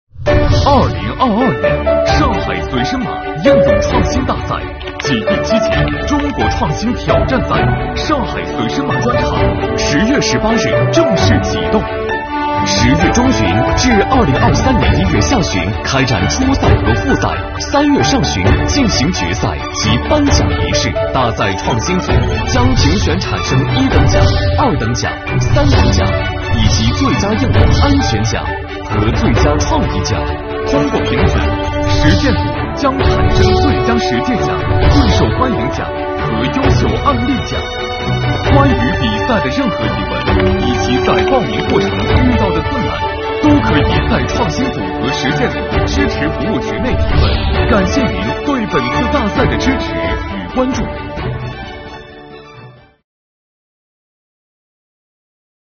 2022年10月18日，上海“随申码”应用创新大赛暨第七届中国创新挑战赛（上海）“随申码”专场采用线上直播的形式启动，并引入虚拟数字主持人，拉开大赛帷幕。